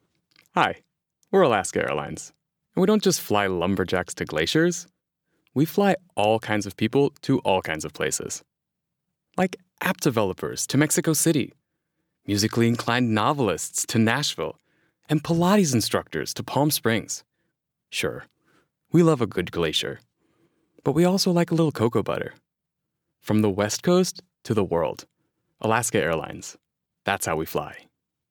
Commercial Sample
Young Adult